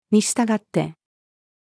in-accordance-with-female.mp3